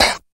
44 CLAP SNR.wav